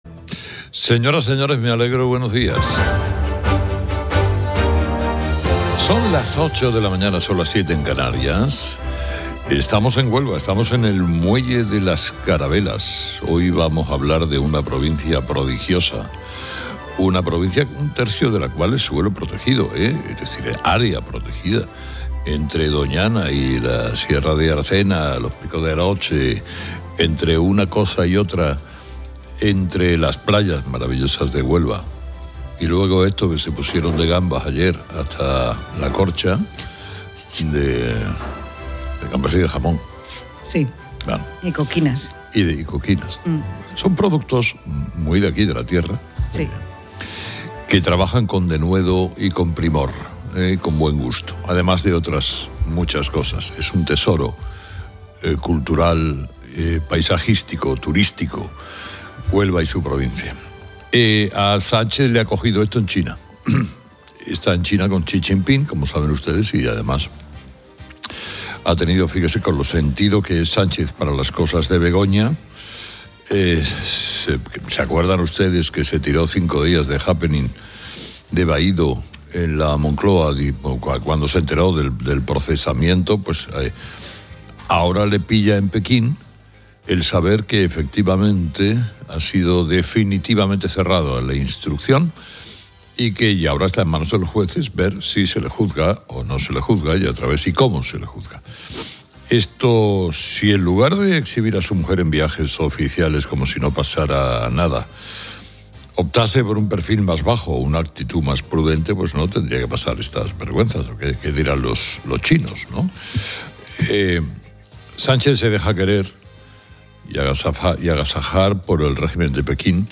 Desde Huelva, el programa destaca su riqueza natural (Doñana, Sierra de Aracena), gastronomía y potencial turístico, si bien se subraya la urgente necesidad de mejorar sus infraestructuras, especialmente las ferroviarias. El tema principal es la conclusión de la instrucción judicial contra Begoña Gómez, esposa del presidente, por tráfico de influencias, corrupción, apropiación indebida y malversación.